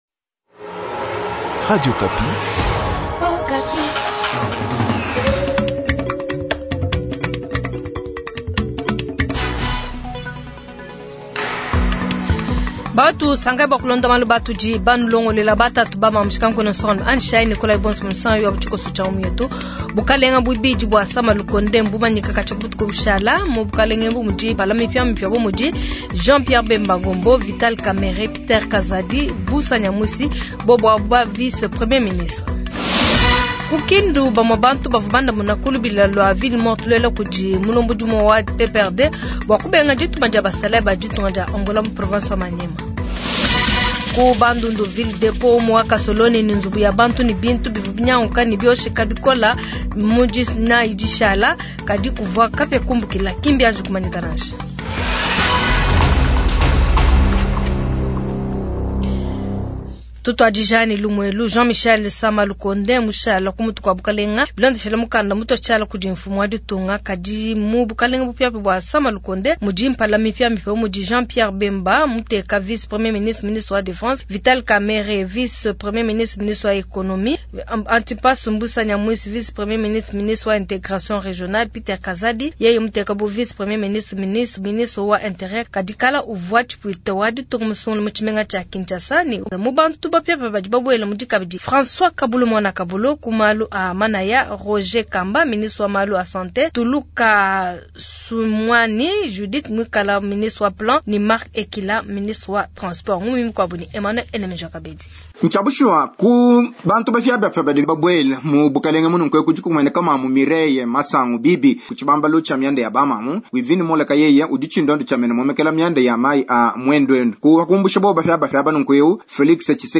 Journal soir
Kin : Vox-Pop attentes des populations face au gouvernement Sama 2